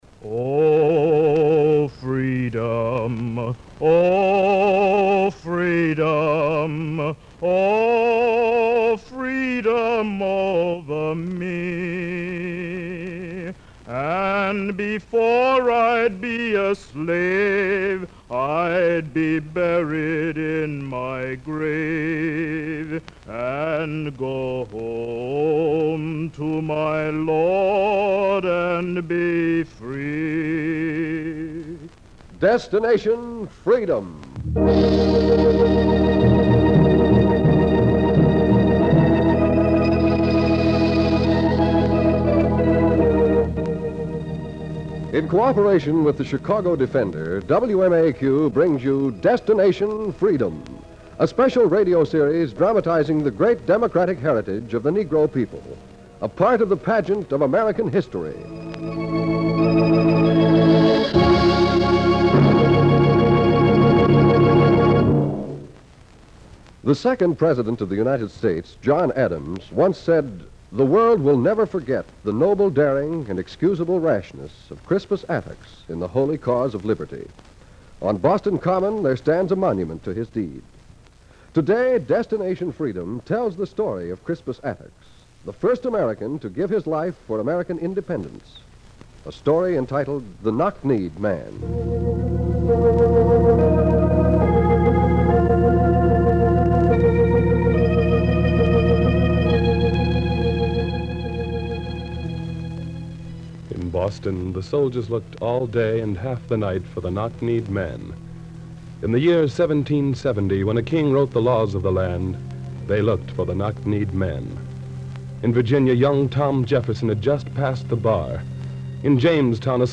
"The Knock-Kneed Man" is the inaugural episode of the Destination Freedom radio series, which originally aired on June 27, 1948.